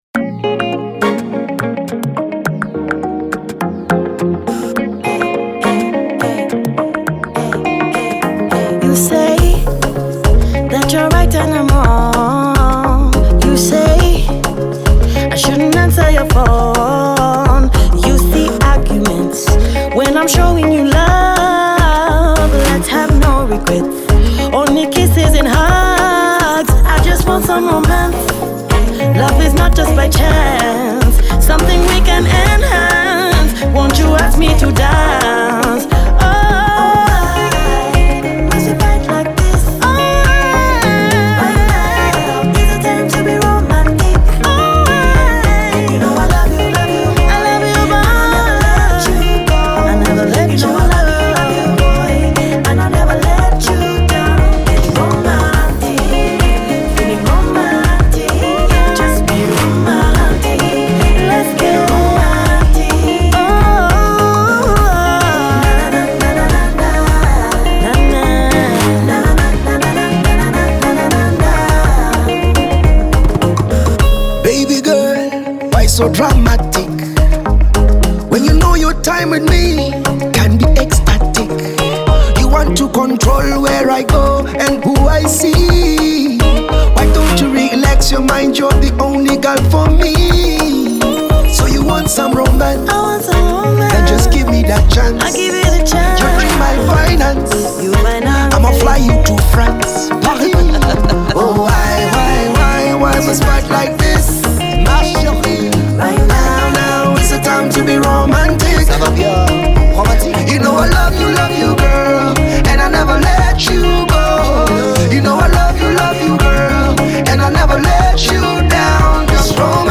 Afro-soul
fun, uplifting and catchy love song